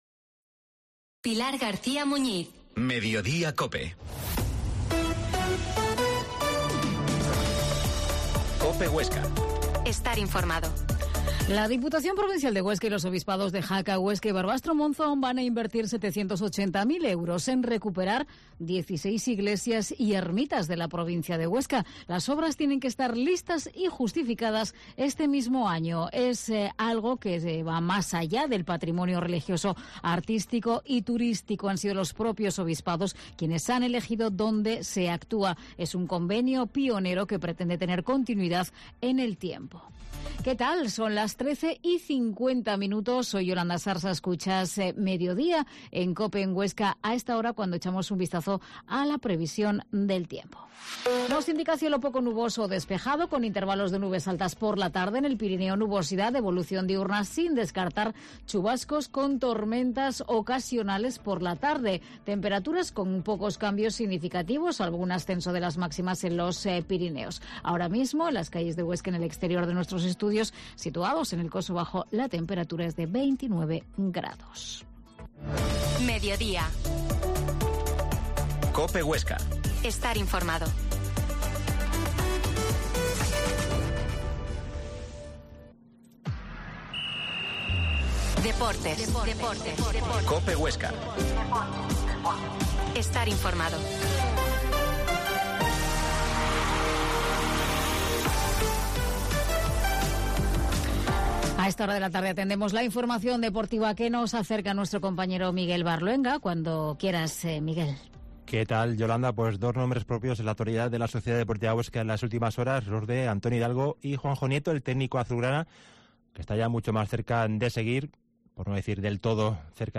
AUDIO: Actualidad. Entrevista Carinsertas